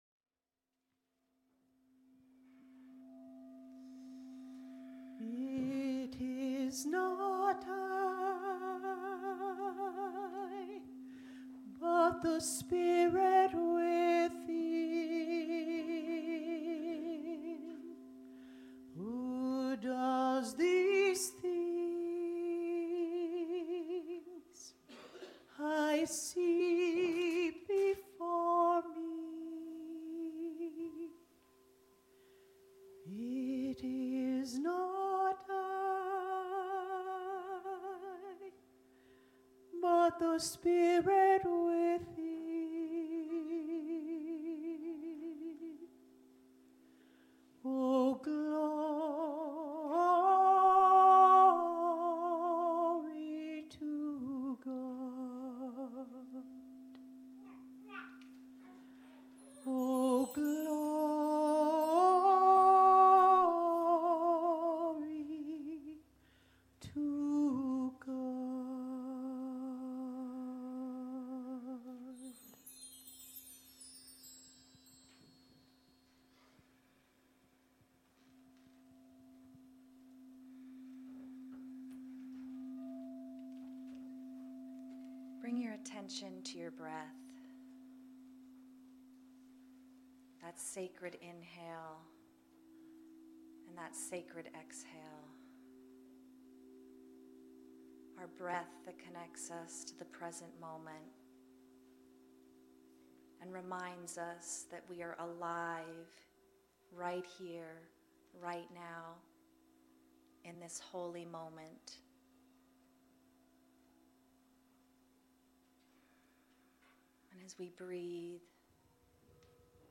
The audio recording (below the video clip) is an abbreviation of the service. It includes the Meditation, Lesson, and Featured Song.